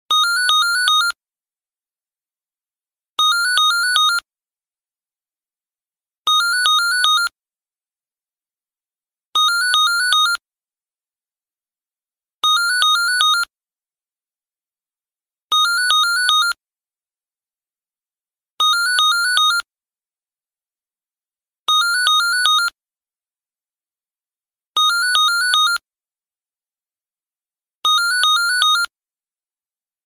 🏥 Telefon Klingelton einer Arztpraxis kostenlos sound effects free download